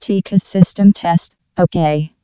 TCAS voice sound samples. ... Artificial female voice.